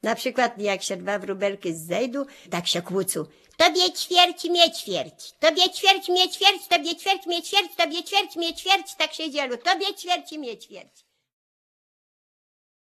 44. Na¶ladowanie ptaków: wróbelki, jaskółecka, dudek, słowik
Birdsong imitation – sparrows, swallow, hoopoe, nightingale
The lullabies, children's songs, counting-out games and children's play contained on this CD come from the Polish Radio collection.